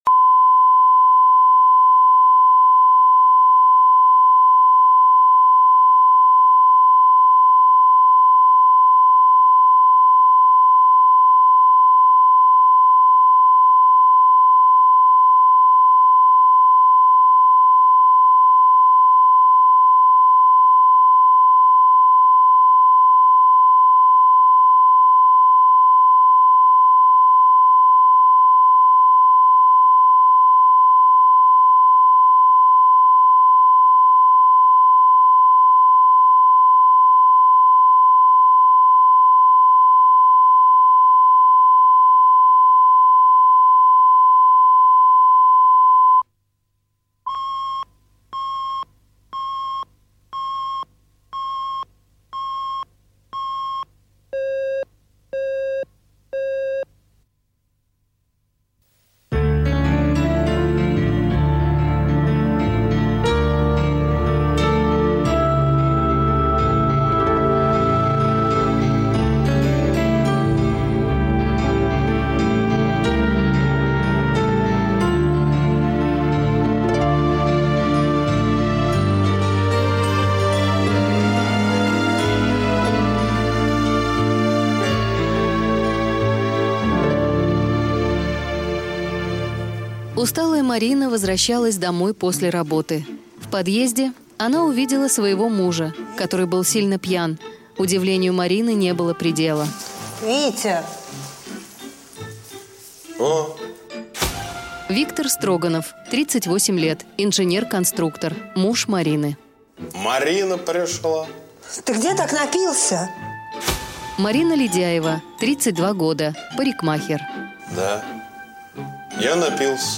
Аудиокнига Суррогатный отец | Библиотека аудиокниг
Прослушать и бесплатно скачать фрагмент аудиокниги